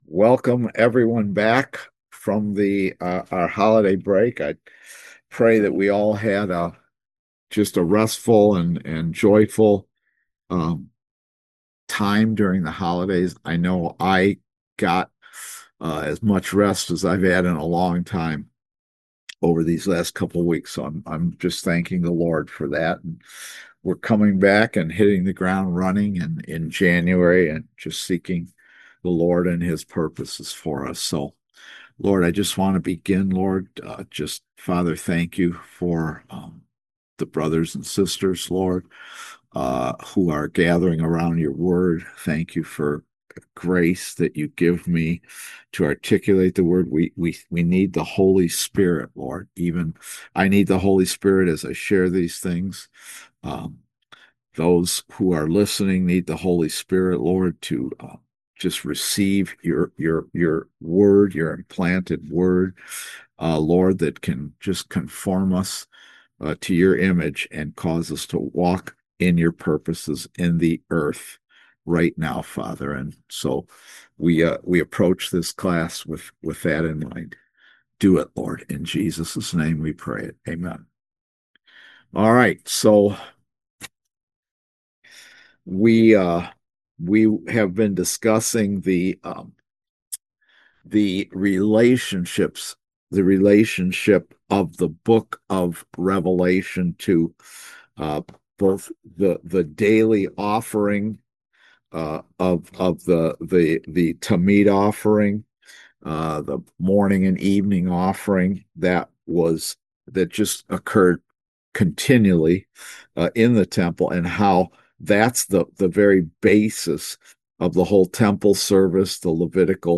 Service Type: Kingdom Education Class